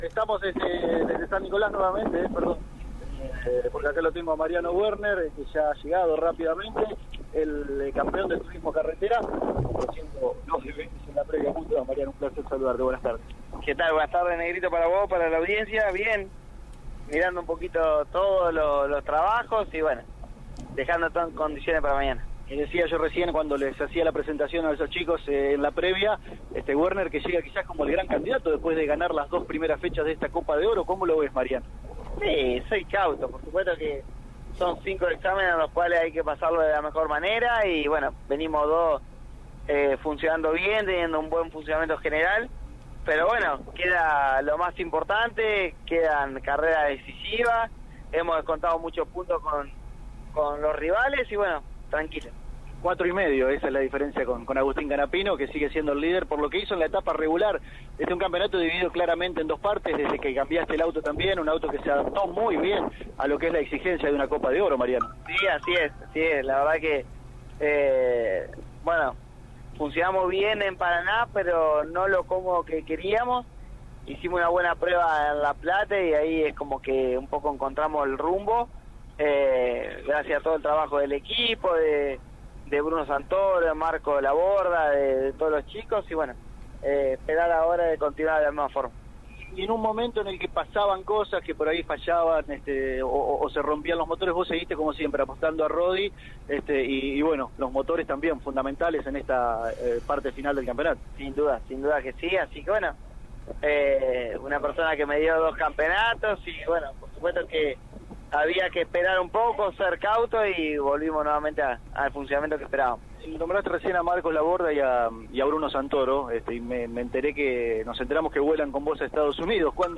A continuación, escuchamos la palabra de Mariano Werner: